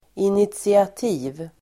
Uttal: [initsiat'i:v]